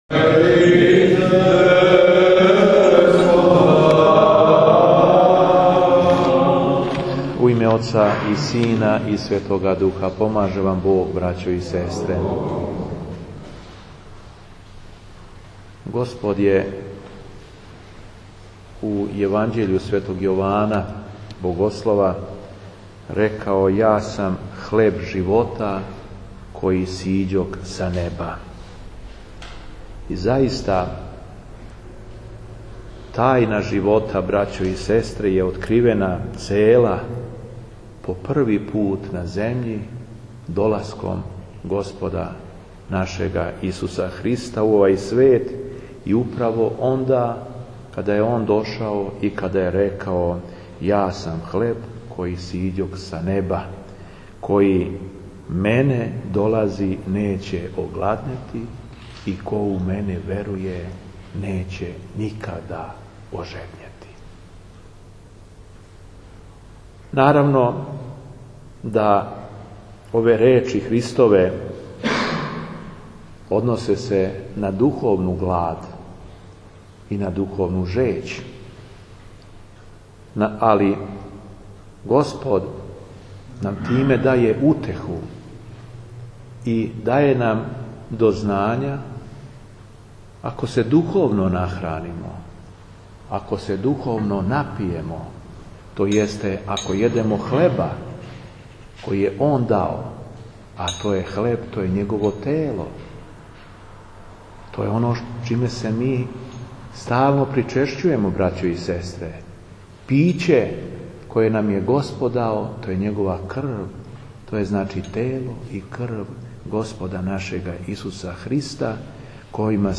Беседа епископа шумадијског Г. Јована
После прочитаног Јеванђеља Владика је одржао надахнуту беседу. Владикине речи усмерене су да укажу на посланичку улогу служења нашег Господа која се односи на животодавни карактер Његовог давања човеку кроз Тело и Крв.
Његово Преосвештенство Епископ шумадијски Г. Јован на дан Светог апостола Тимотеја и преподобног мученика Анастасија 4. фебруара 2011. године служио је Свету Архијерејску Литургију у храму Свете Петке у Виноградима.